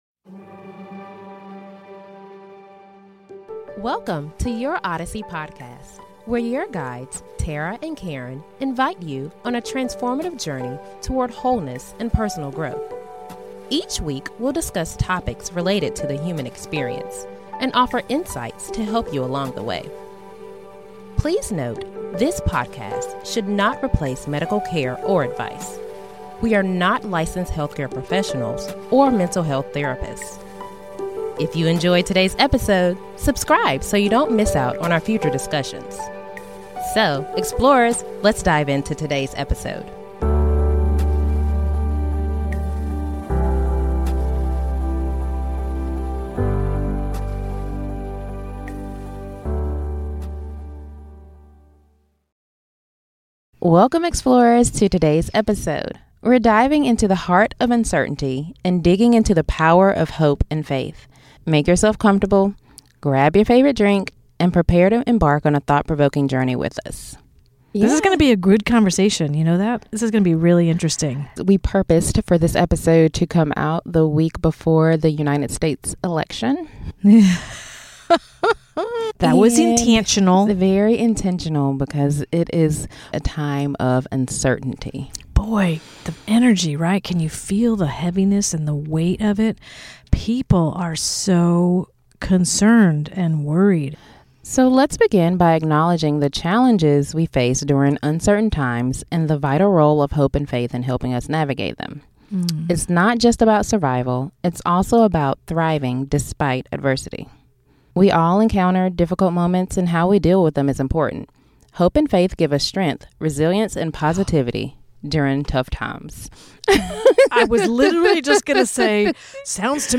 Whether you're sipping your morning coffee or winding down after a long day, this conversation is designed to inspire and uplift.